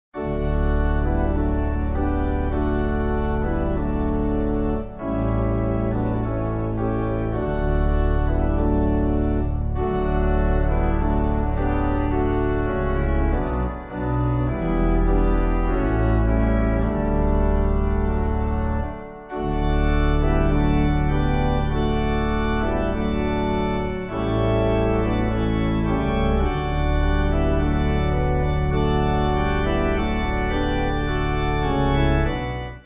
Organ
(CM)   4/D/Eb